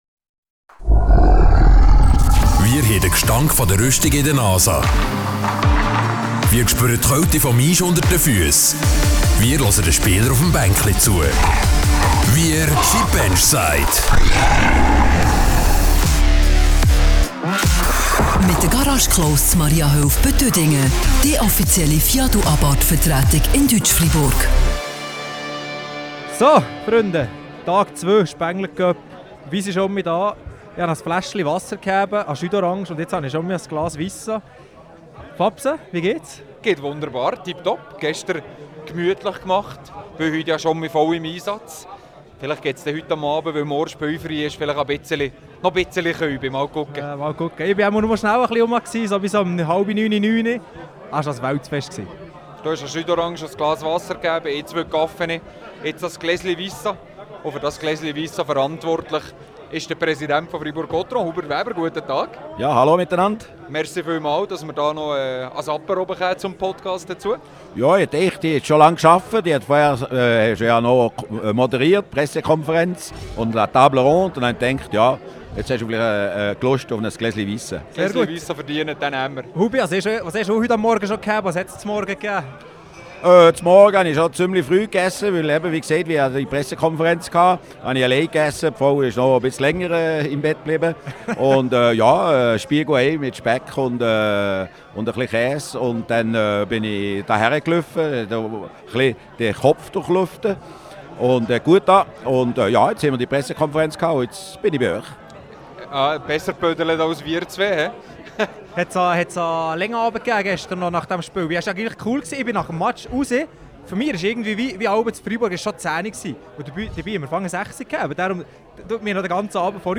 Beschreibung vor 1 Jahr Der erste Tag am Spengler Cup ist vorbei, leider ohne Sieg der Drachen. Heute gehts weiter, auf dem Eis gegen Kärpät Oulu und im Freiburger Chalet mit benchside.